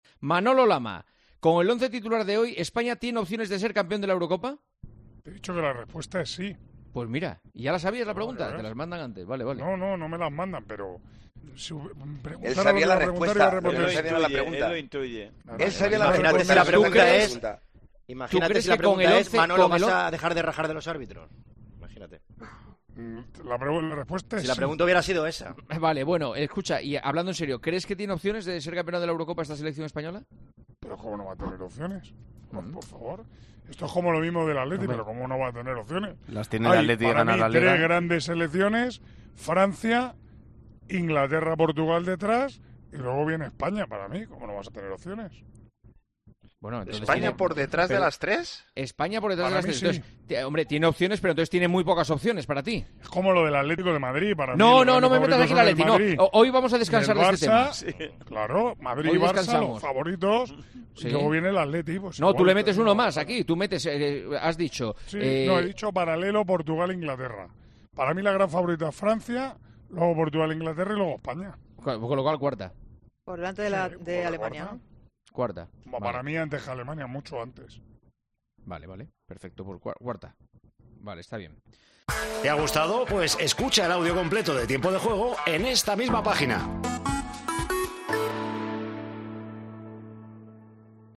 El narrador de los partidos de España en Tiempo de Juego responde a una pregunta de un oyente sobre la selección española de cara a la Eurocopa que viene.